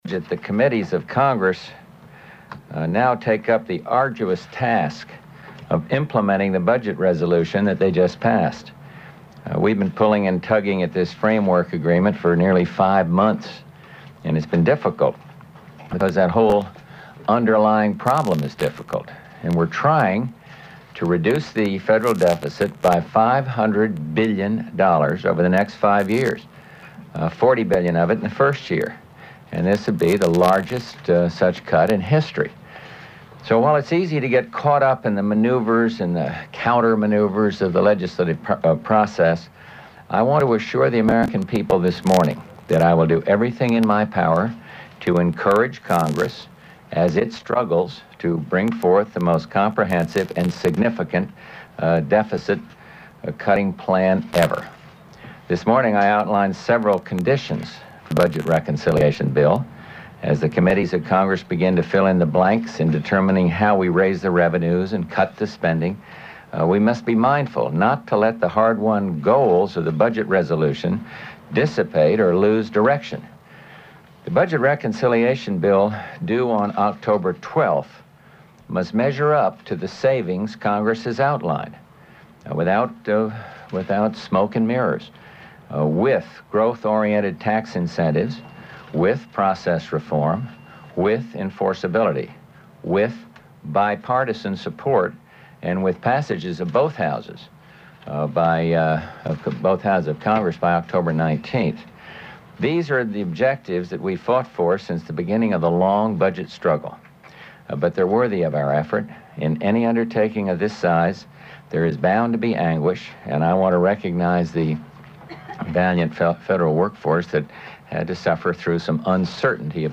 Presidential press conference dealing mainly with budget reduction, but dealing also with events in the Middle East
Broadcast on CNN, October 9, 1990.